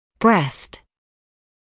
[brest][брэст]грудь, молочная железа; грудная клетка